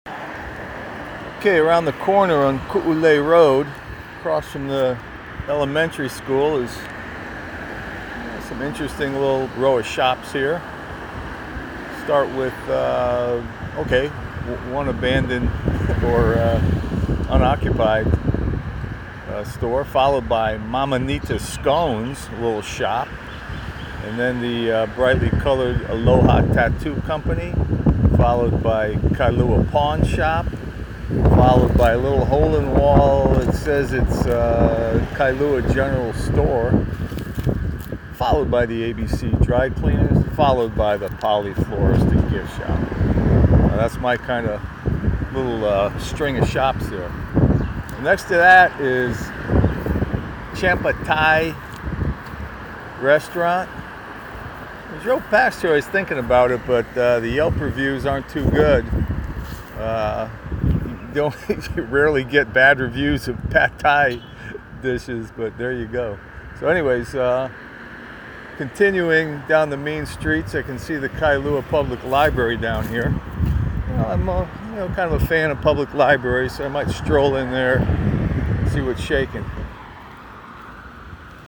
String of shops on Kuulei Road….why do tattoo parlors and pawn shops go so well side-by-side?